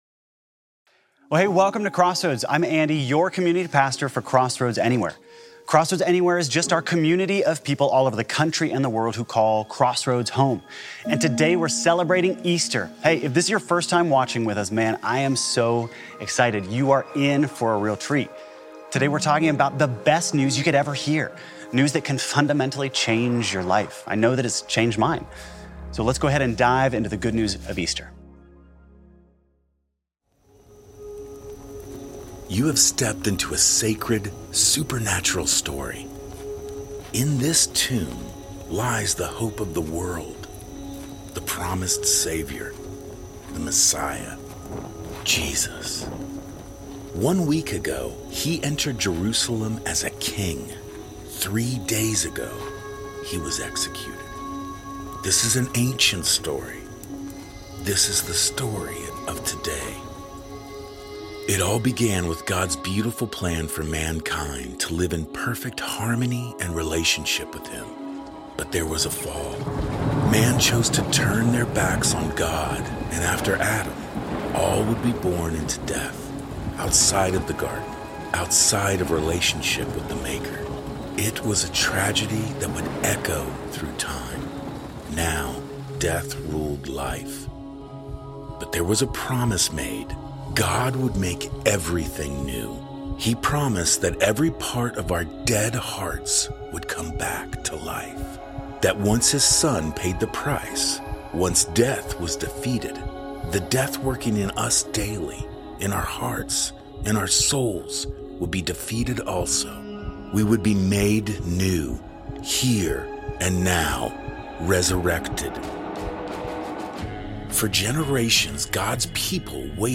When Jesus rose from the dead, he conquered death forever and now he is calling us to rise up out of all the dead places in our own lives to live fully alive. A triumphant, joyful, hope-filled celebration of Jesus' resurrection and the gift of new life!